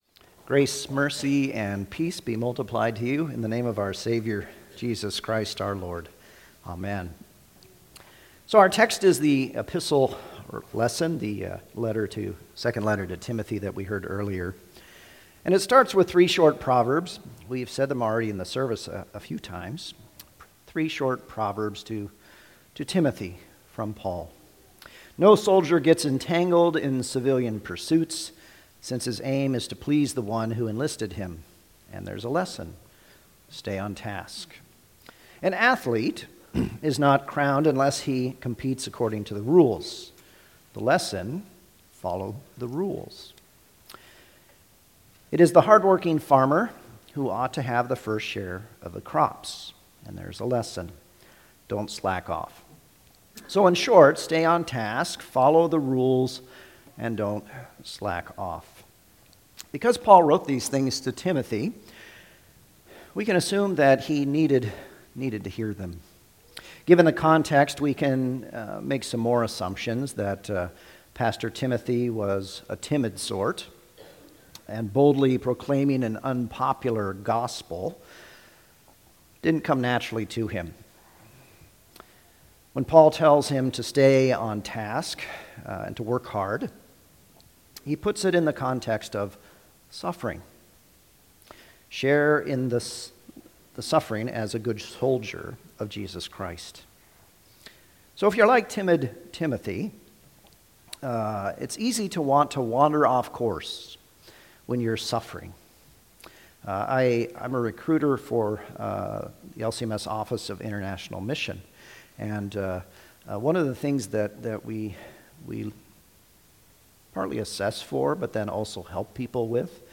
Sermon-2025-10-12.mp3